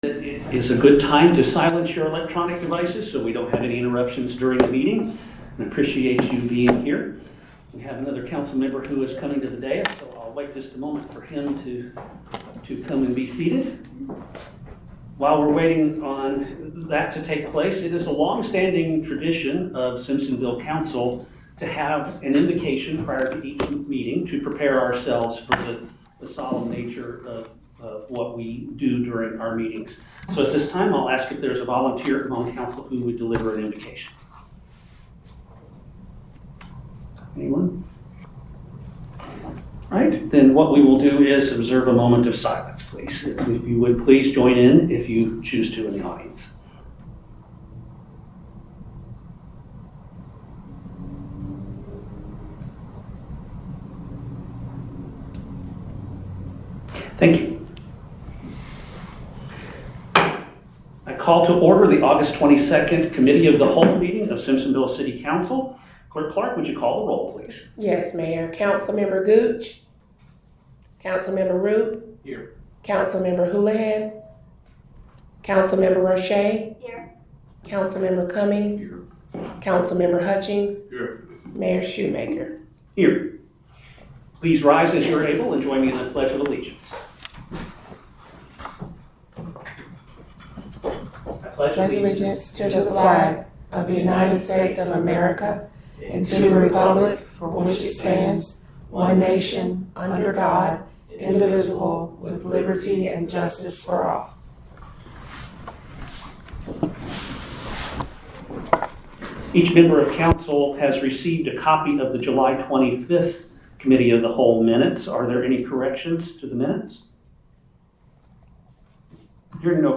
City Council Committee of the Whole Meeting